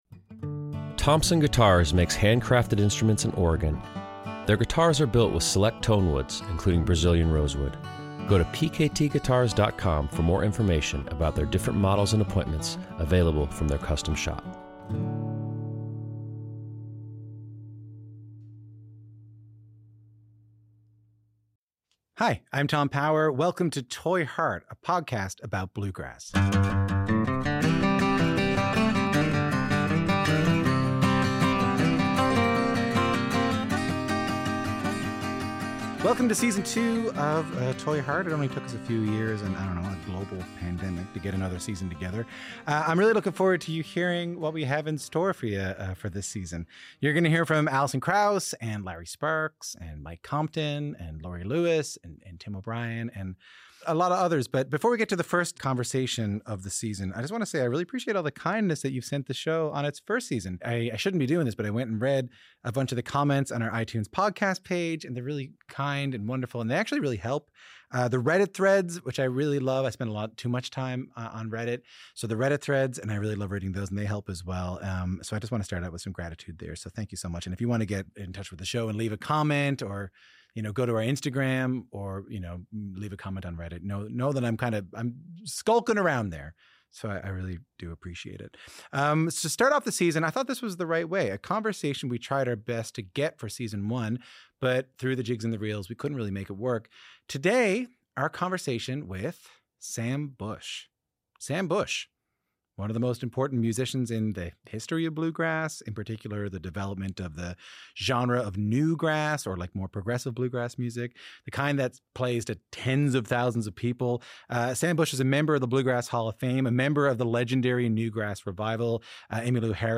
Newgrass luminary Sam Bush joins host Tom Power for the highly anticipated debut episode of Toy Heart Season 2. Bush – the celebrated mandolinist, Bluegrass Hall of Famer, and co-founder of New Grass Revival and the Telluride House Band – opens up about his illustrious career, from his early days of fiddle contests in Weiser, Idaho, to the pivotal moments learning at the feet of influential figures like Bill Monroe.